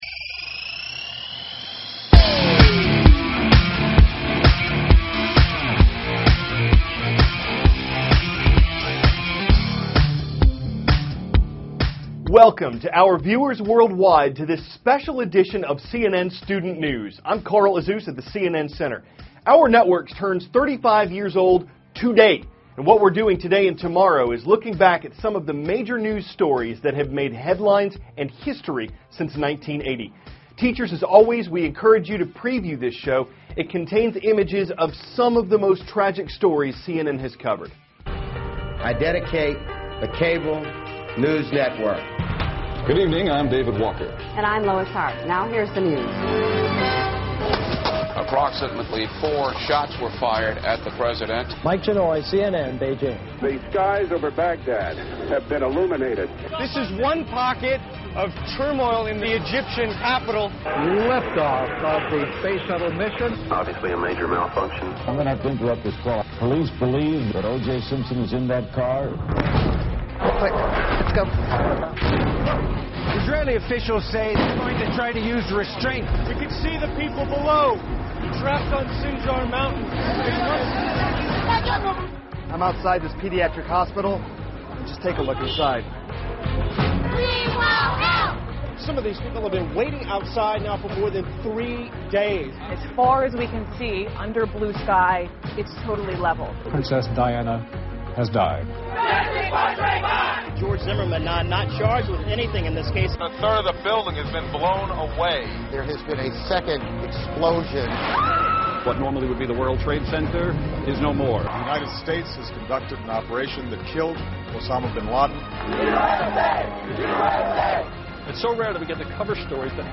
(cnn Student News) -- June 1, 2014